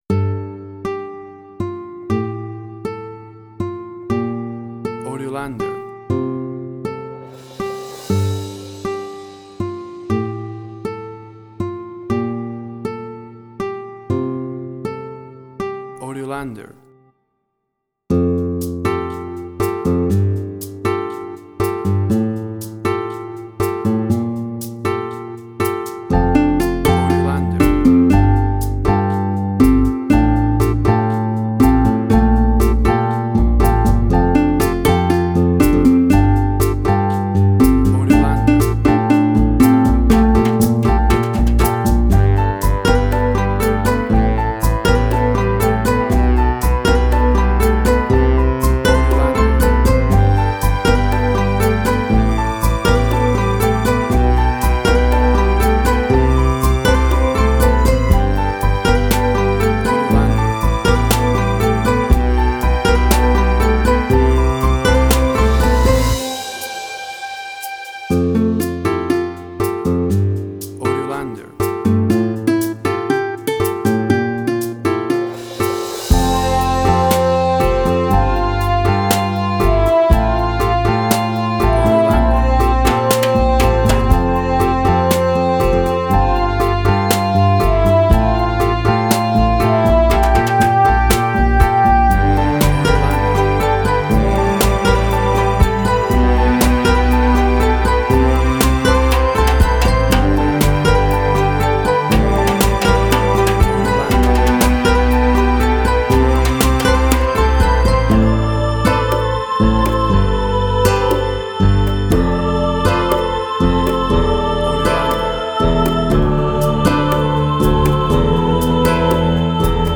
Latin Fusion Orchestral Hybrid
Tempo (BPM): 120